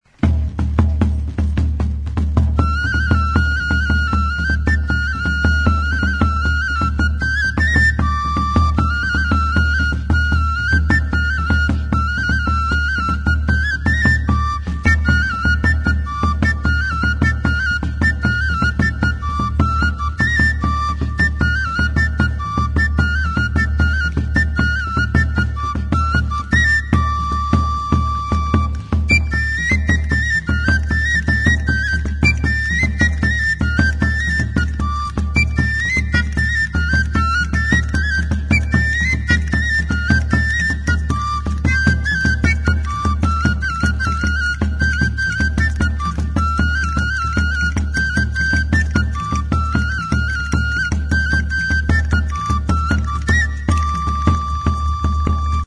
PINGACHO. Galandum galundaina.
FLAUTA; Flauta de tamborileiro
Aerophones -> Flutes -> Fipple flutes (one-handed)
Hiru zuloko flauta zuzena da.